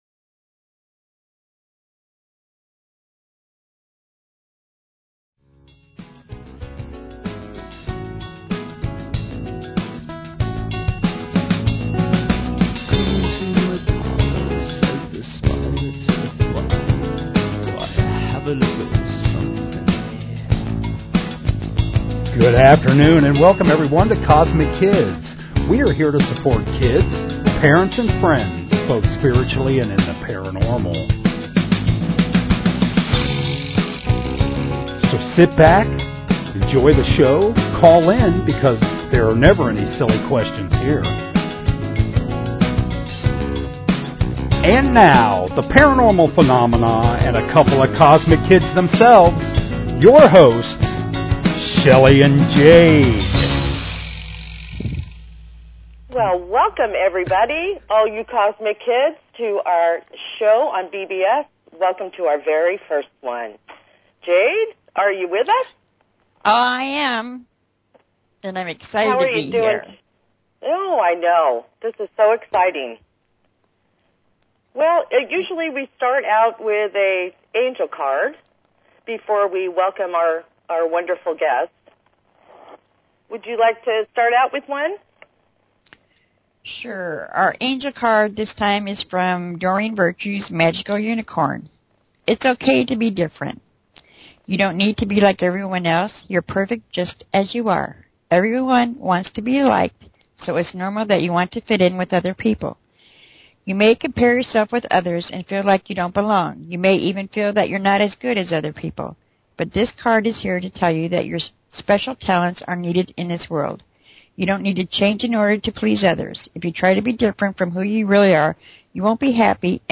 Talk Show Episode, Audio Podcast, Kozmic_Kids and Courtesy of BBS Radio on , show guests , about , categorized as